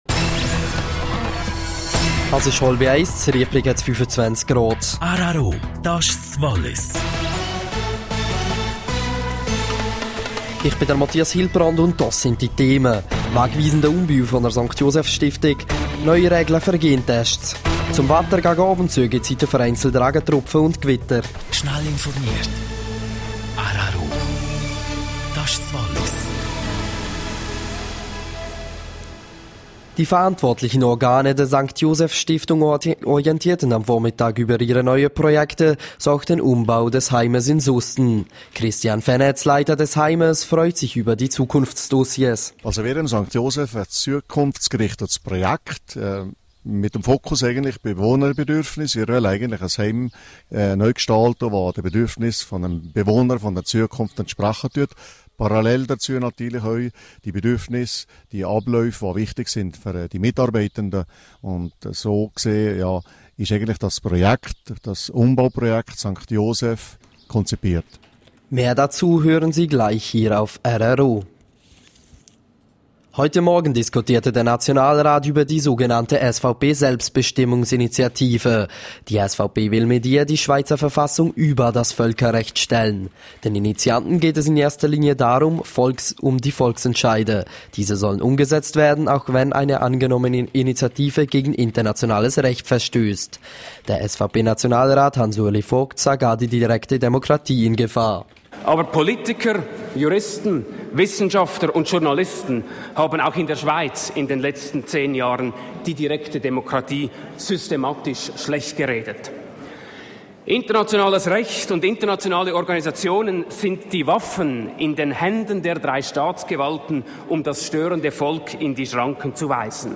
12:30 Uhr Nachrichten (4.56MB)